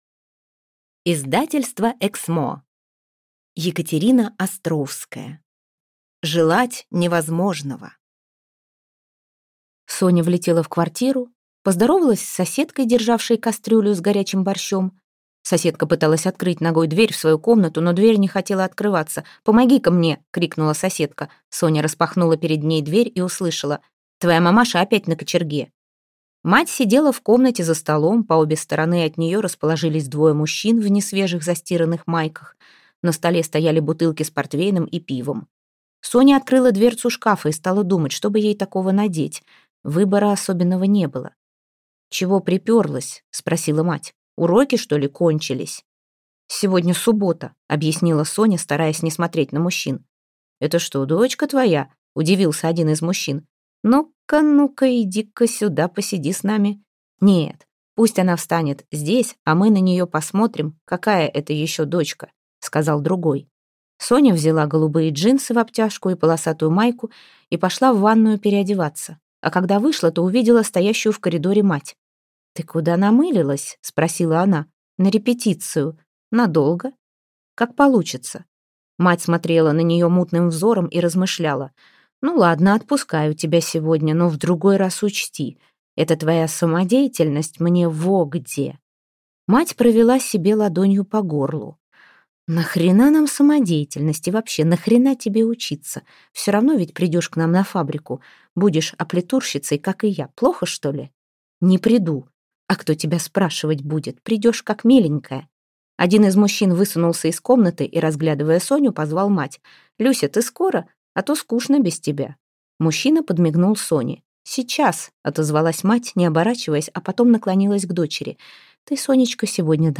Аудиокнига Желать невозможного | Библиотека аудиокниг